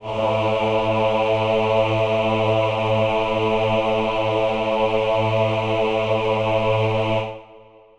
Choir Piano (Wav)
A2.wav